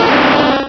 Cri d'Ectoplasma dans Pokémon Rubis et Saphir.